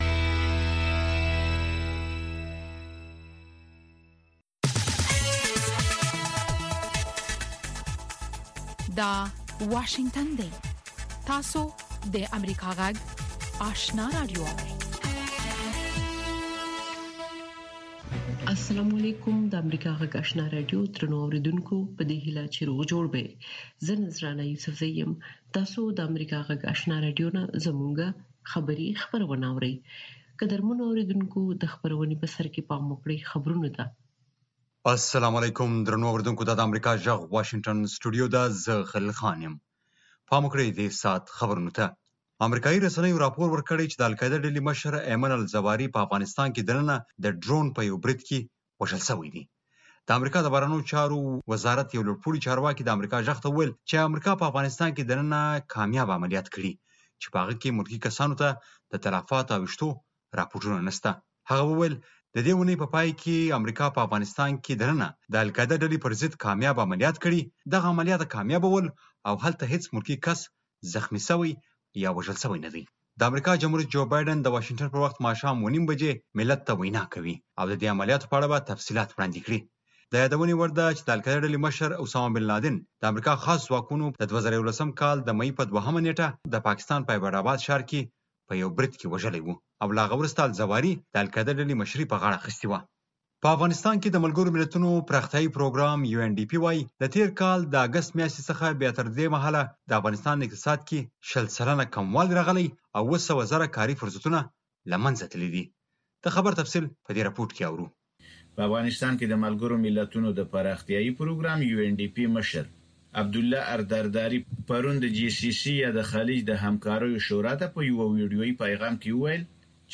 سهارنۍ خبري خپرونه